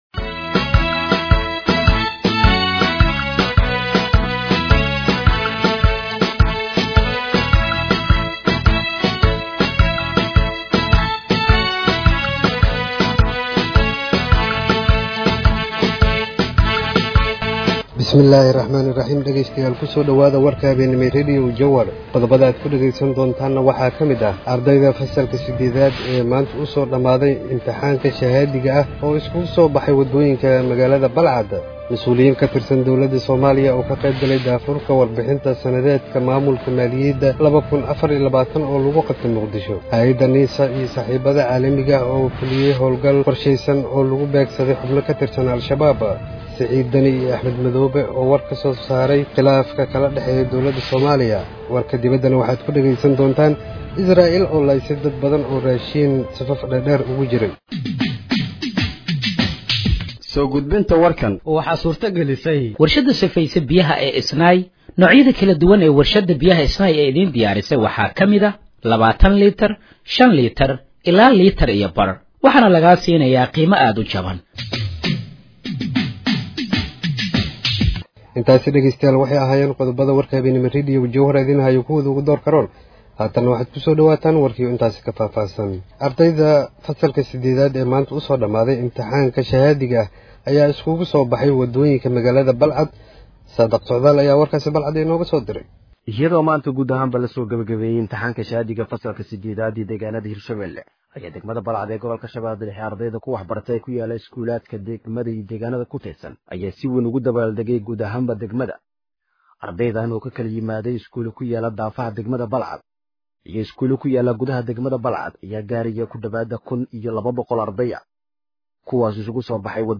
Dhageeyso Warka Habeenimo ee Radiojowhar 24/06/2025